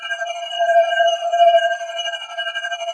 shield_on.WAV